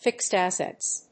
アクセントfíxed ássets